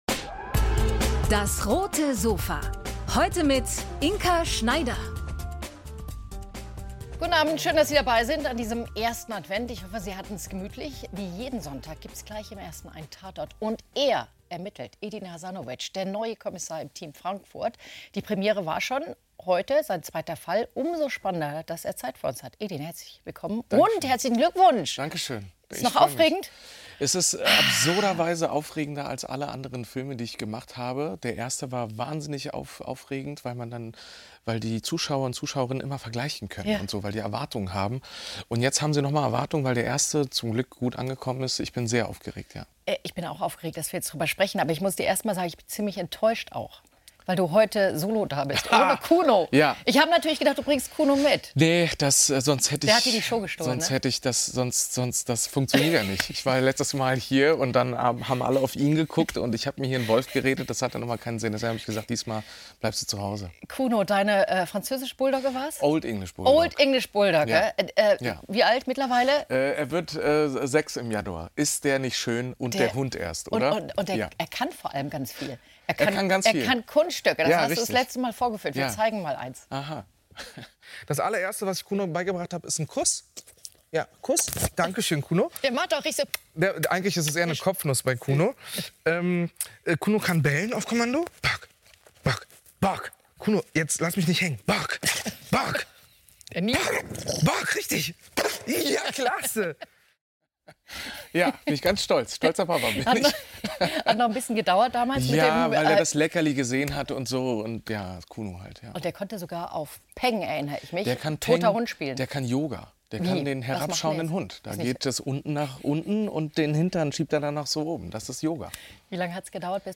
Edin Hasanović über seine Rolle als Tatort-Kommissar ~ DAS! - täglich ein Interview Podcast